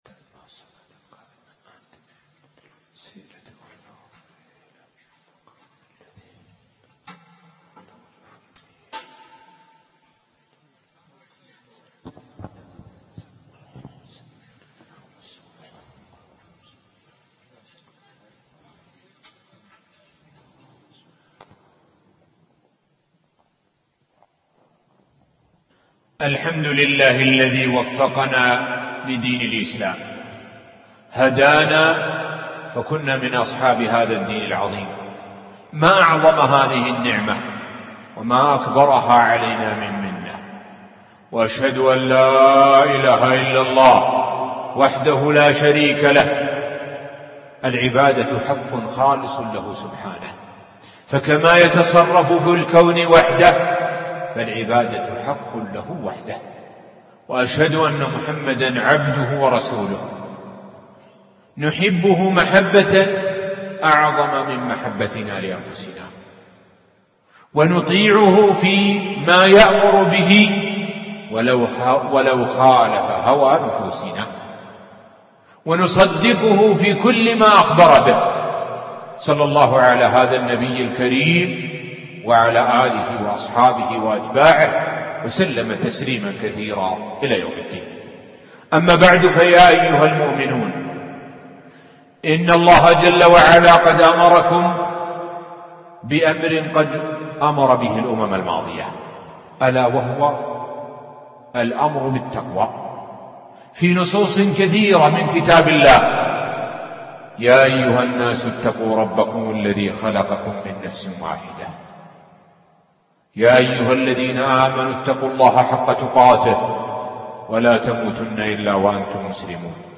الموقع الرسمي لفضيلة الشيخ الدكتور سعد بن ناصر الشثرى | خطبة الجمعة بعنوان كونو شامة في الناس